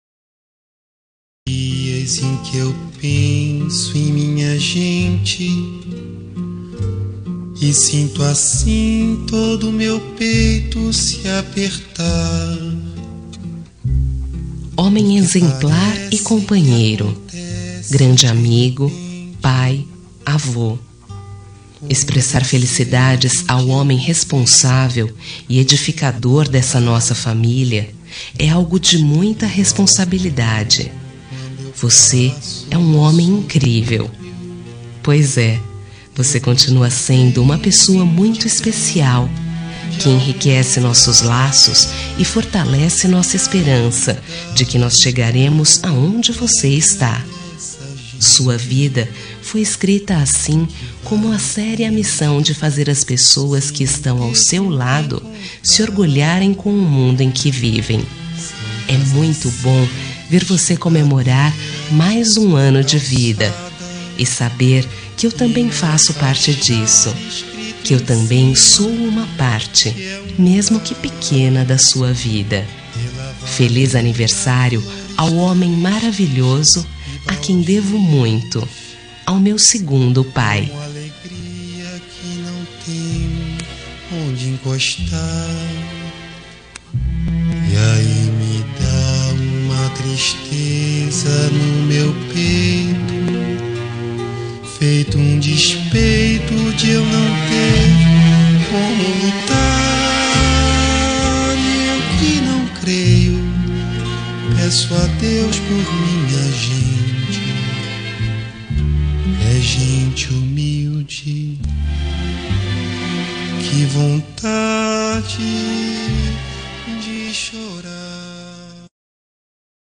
Aniversário de Avô – Voz Feminina – Cód: 2093